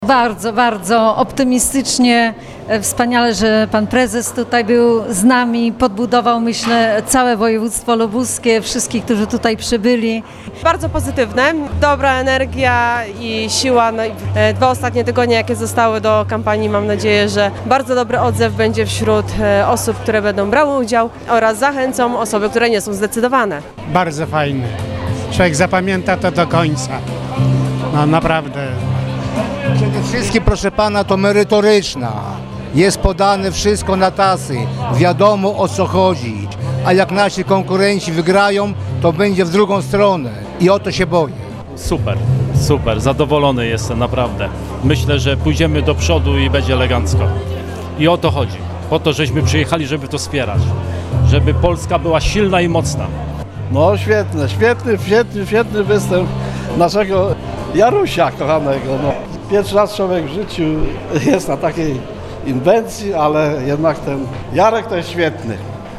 Komentarze uczestników konwencji PiS